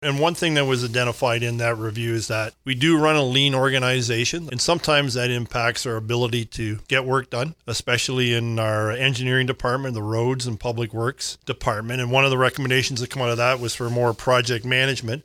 Mayor Colin Grantham explains.